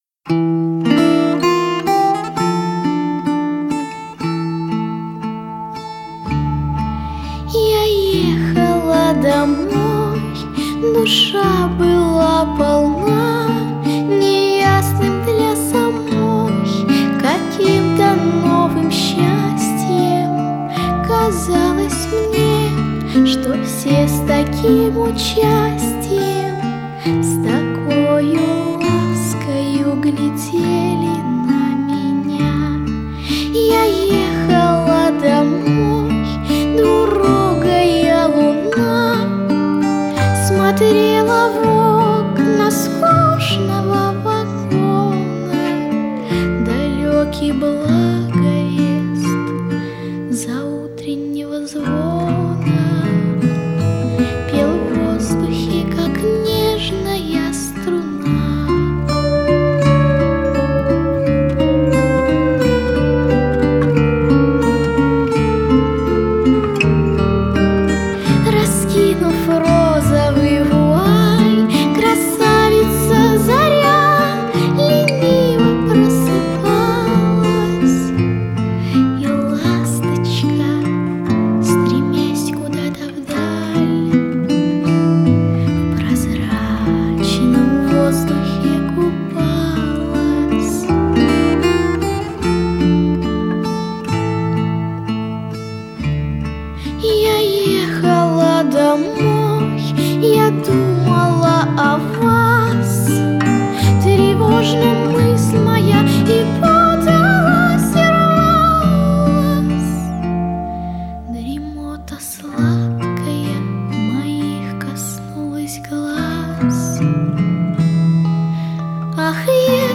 Først en romance: